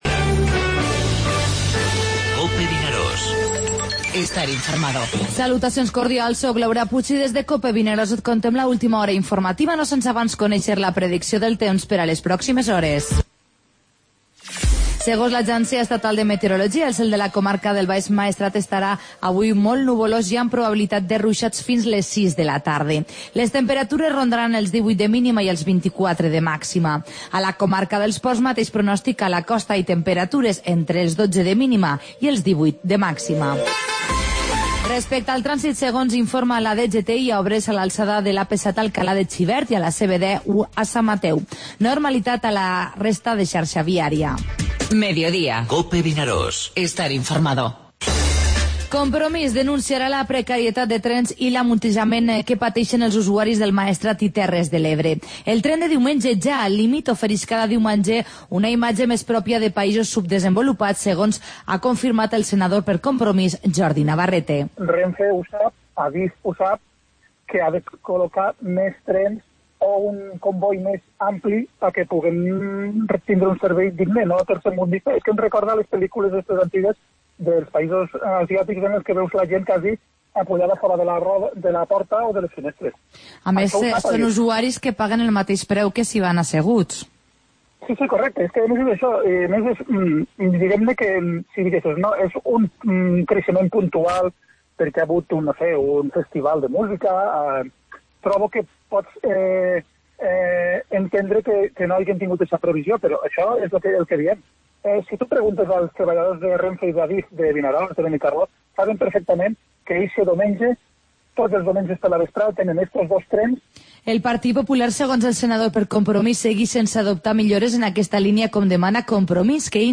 Informativo Mediodía COPE al Maestrat (dilluns 26 de setembre)